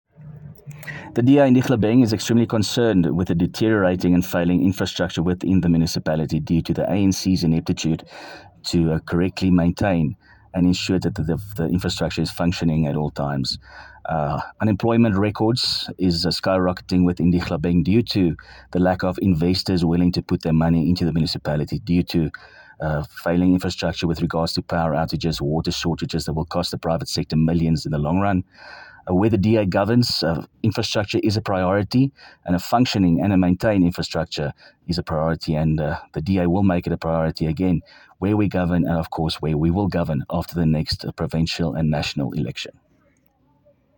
Issued by Willie Theunissen – DA Councillor Dihlabeng Local Municipality
Afrikaans soundbites by Cllr Willie Theunissen and